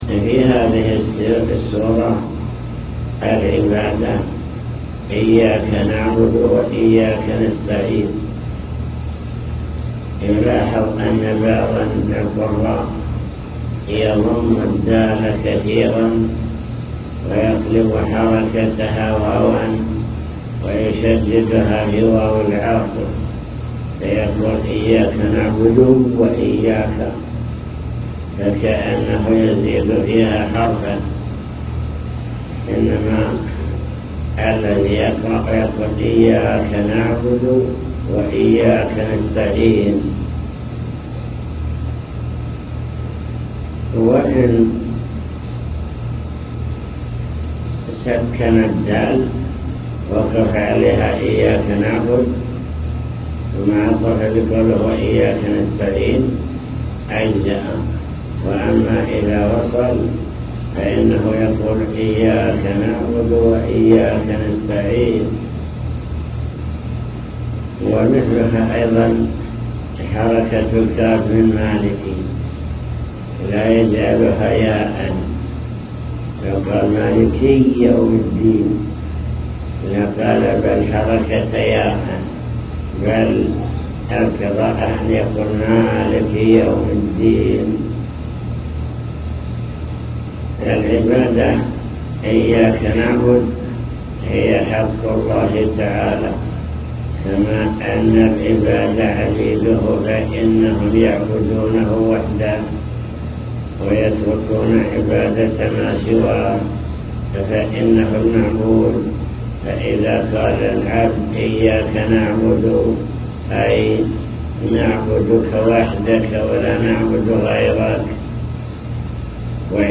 المكتبة الصوتية  تسجيلات - لقاءات  حول أركان الصلاة (لقاء مفتوح) من أركان الصلاة: قراءة الفاتحة